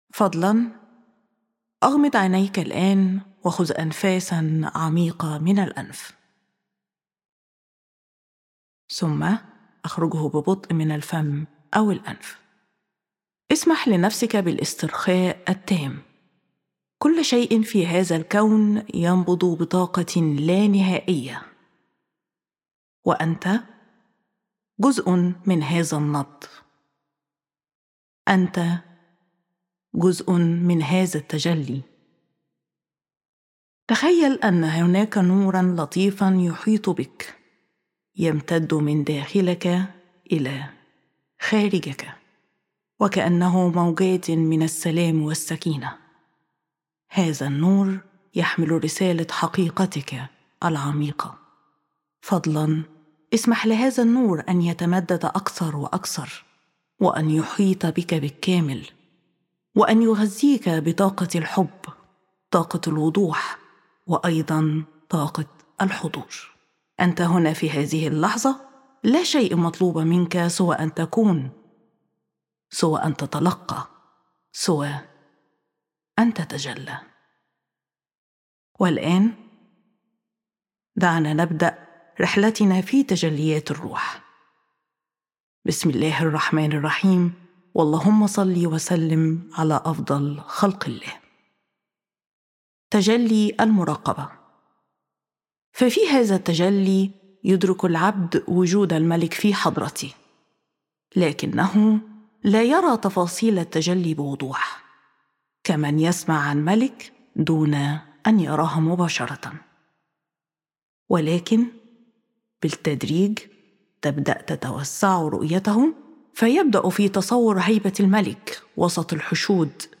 الدورة تسجيلات صوتية – أكثر من 150 تجلي .
التسجيلات بجودة عالية بأحدث استديوهات الصوت و التي تم فيها تسجيلات مجموعة تأملات اسماء الله الحسنى من قبل .